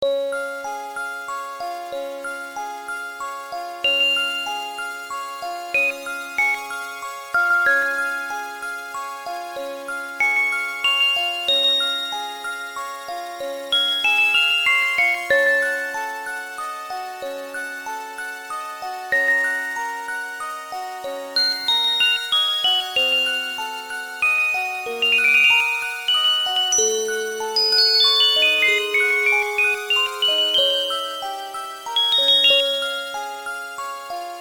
変二長調（オルゴール風